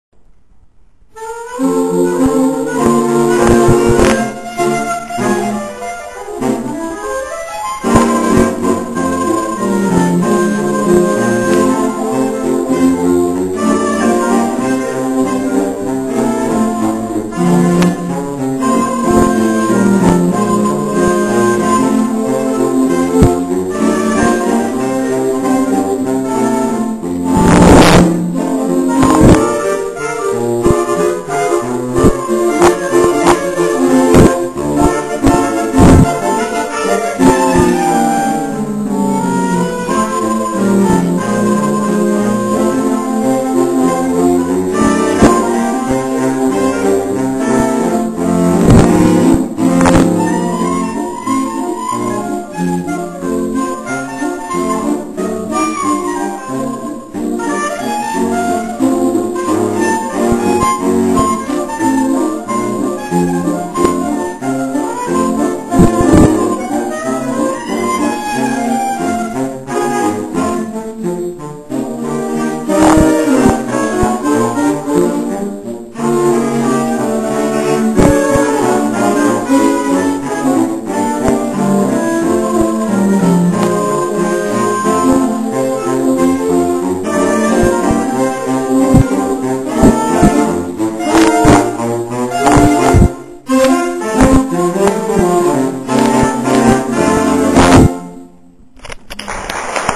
Saxofonové kvarteto Moravia
26. září 2006 - 23. komorní koncert na radnici - Podrobný program koncertu "Melodie dýchajícího kovu"
sopránový saxofon
altový saxofon
tenorový saxofon
barytonový saxofon
Ukázkové amatérské nahrávky WMA: